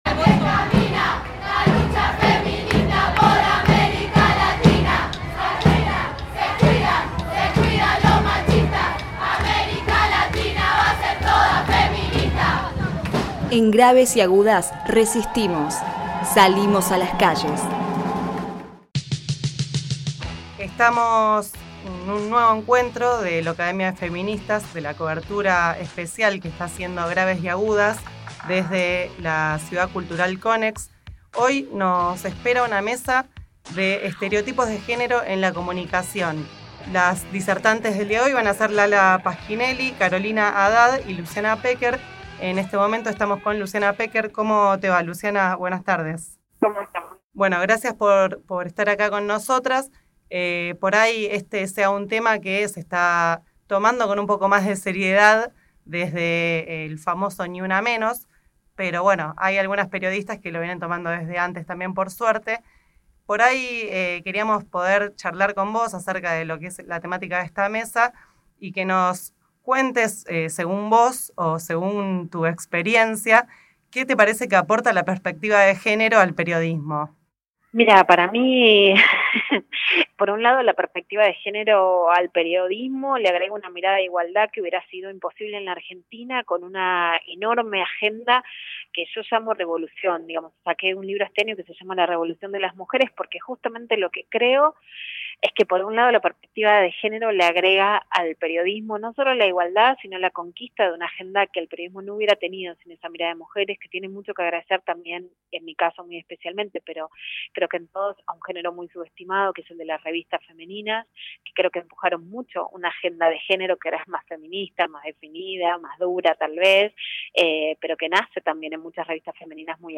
La revolución de las mujeres | Entrevista